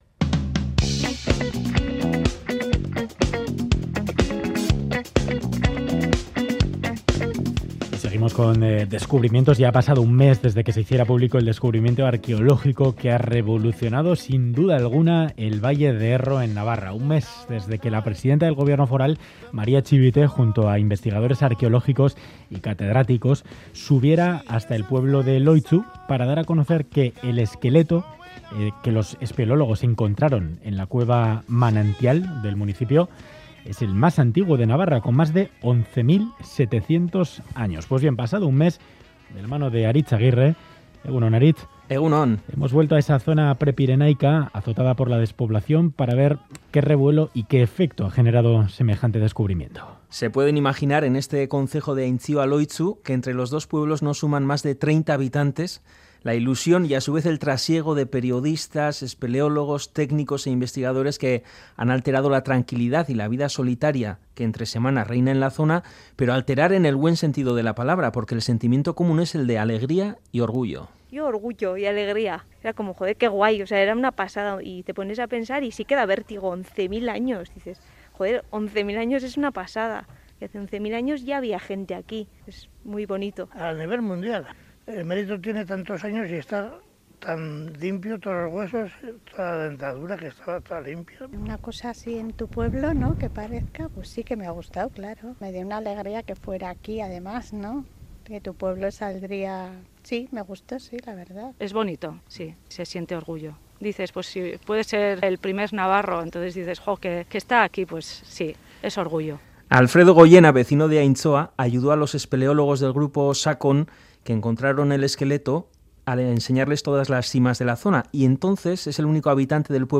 Audio: Hombre de Loitzu testimonios espeleólogo vecinos de la zona